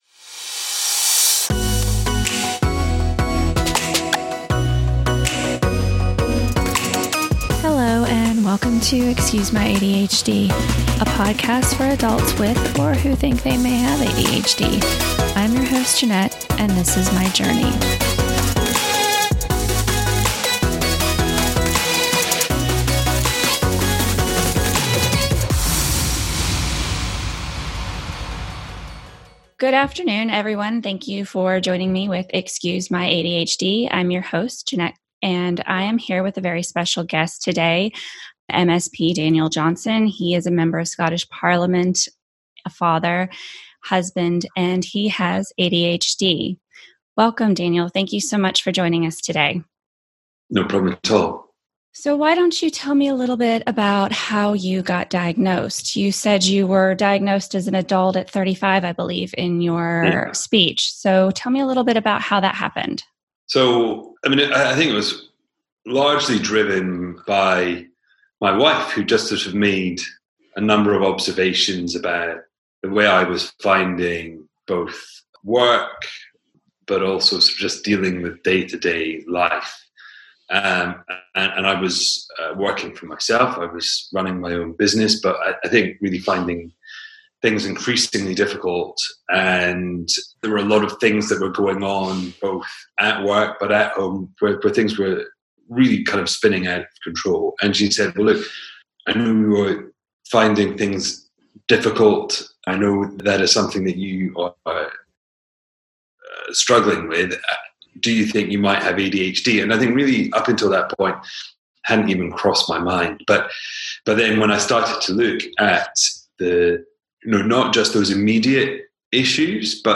Episode 5: Guest Interview with MSP Daniel Johnson